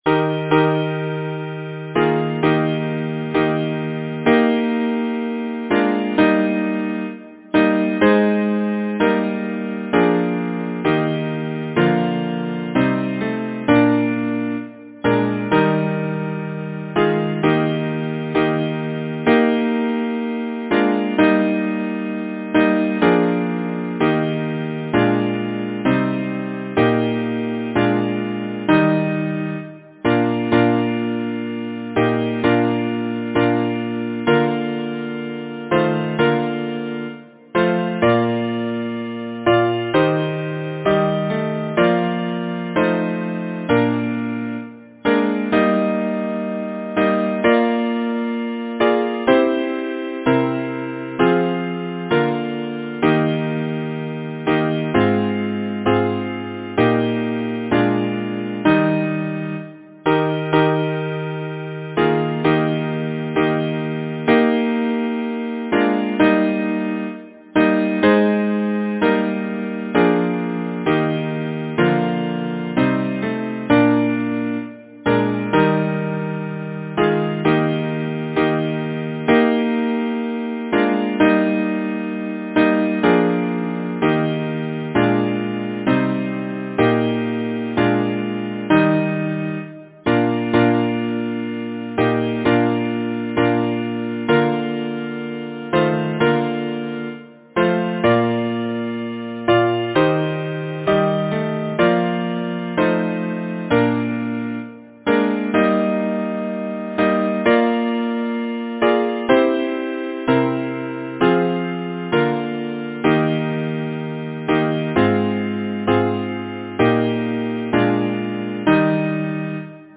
Title: Sabbath evening twilight Composer: George J. Webb Lyricist: William Cutter Number of voices: 4vv Voicing: SATB Genre: Secular, Partsong, Hymn
Language: English Instruments: A cappella